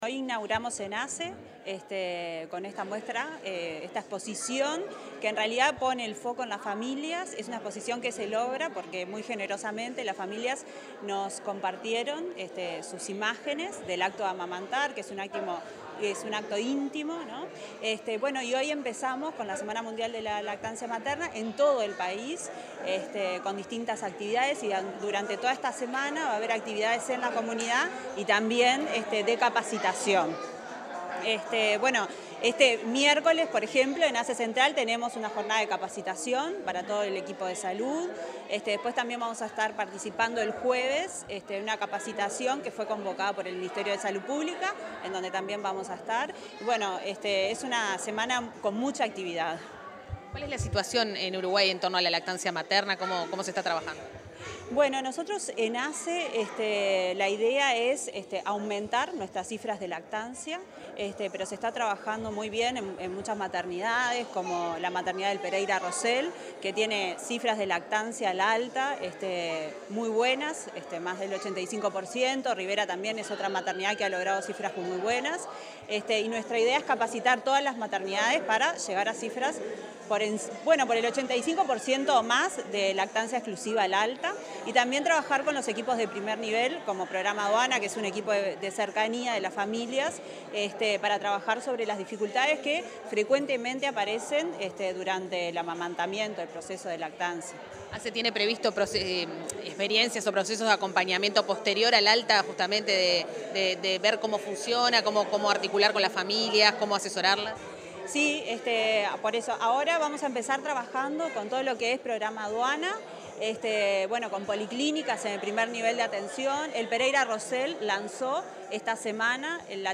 Declaraciones de la directora de Salud de Niñez y Adolescencia de ASSE, Estefanía Cabo
En la inauguración de una muestra fotográfica sobre lactancia materna, la directora de Salud de Niñez y Adolescencia de la Administración de los Servicios de Salud del Estado (ASSE), Estefanía Cabo, detalló las distintas actividades previstas para entre el 1.° y el 7 de agosto, con motivo de la Semana de la Lactancia Materna.